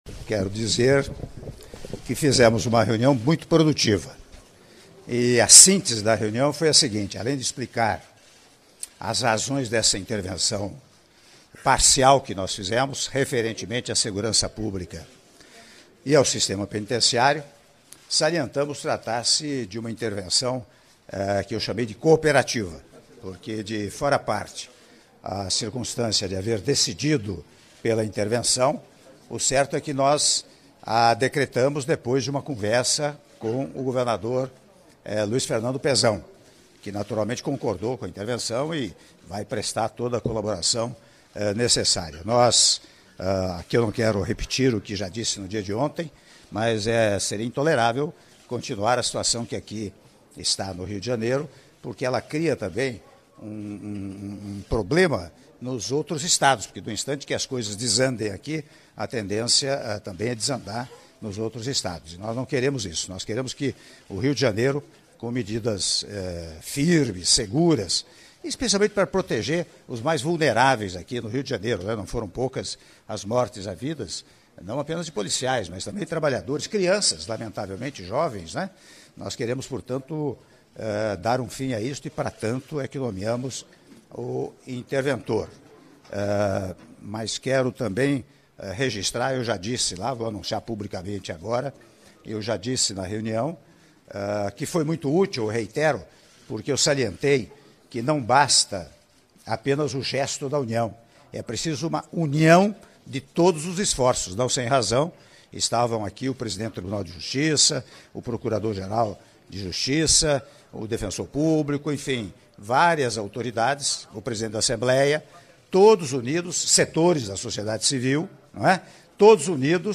Áudio da entrevista coletiva concedida pelo Presidente da República, Michel Temer, após Reunião de Trabalho sobre Segurança - Rio de Janeiro/RJ - (02min45s) — Biblioteca